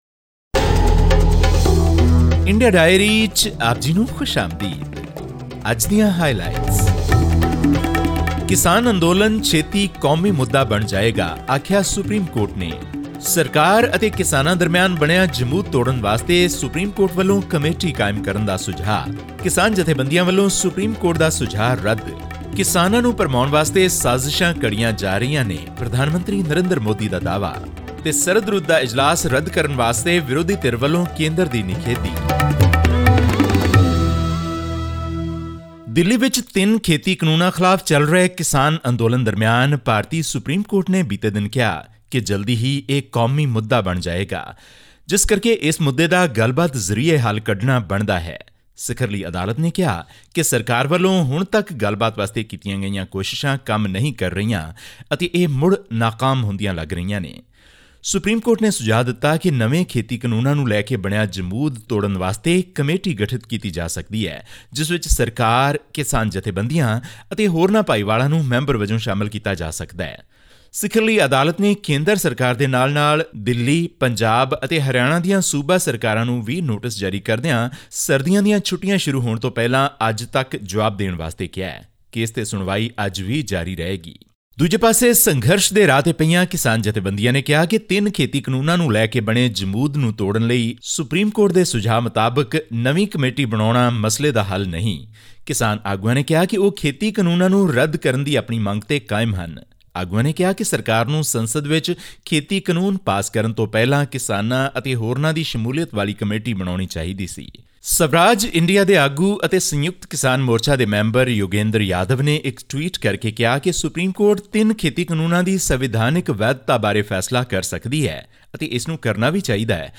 In our weekly news wrap from India, this week we bring you updates on the Indian farmers’ protest, the Modi government’s response to the farmers’ unions, and much more...